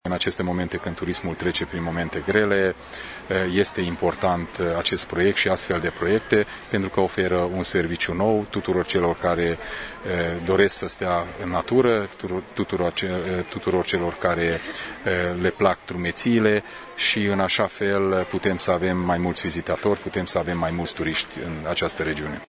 Și președintele CJ Mureș, Peter Ferenc este de părere că proiectul Via Transilvanica ar putea contribui la creșterea numărului de turiști în zonă, într-o perioadă dificilă pentru acest domeniu: